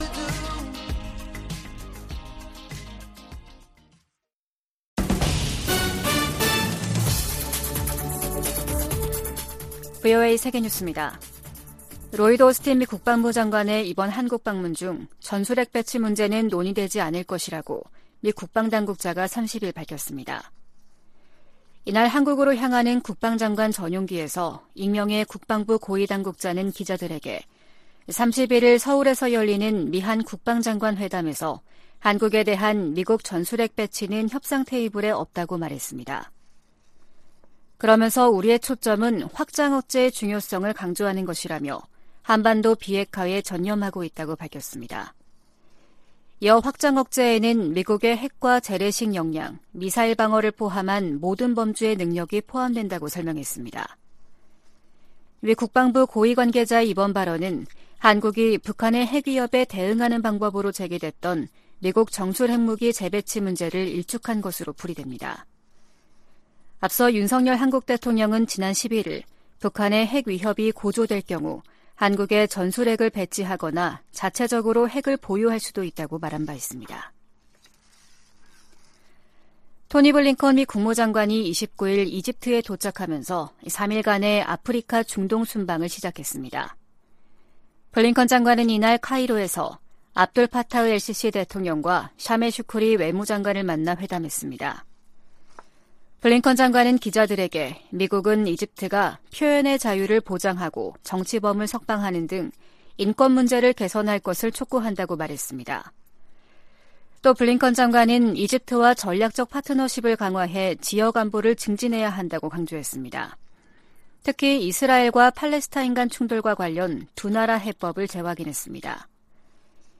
VOA 한국어 아침 뉴스 프로그램 '워싱턴 뉴스 광장' 2023년 1월 31일 방송입니다. 백악관은 북한이 ‘사이버 업계 전반의 취약한 보안으로 10억 달러 이상을 탈취해 미사일 프로그램에 자금을 조달하는 것이 가능했다’고 지적했습니다. 서울에서 열리는 미한 국방장관 회담에서 확장억제 실행력 강화 방안이 집중 논의될 것이라고 미 군사 전문가들이 전망했습니다.